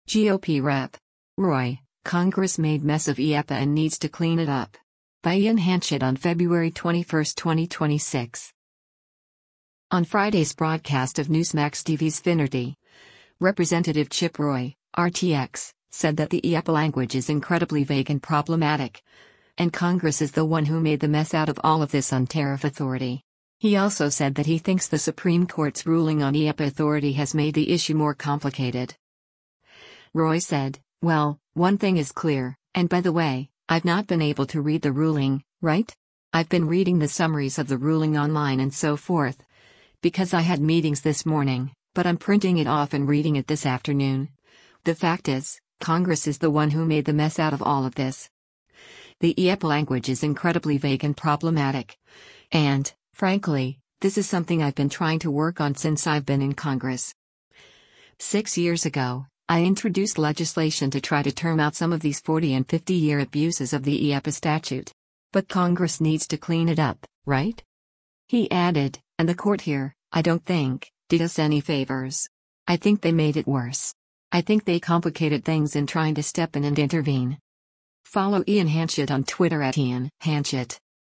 On Friday’s broadcast of Newsmax TV’s “Finnerty,” Rep. Chip Roy (R-TX) said that “The IEEPA language is incredibly vague and problematic,” and “Congress is the one who made the mess out of all of this” on tariff authority. He also said that he thinks the Supreme Court’s ruling on IEEPA authority has made the issue more complicated.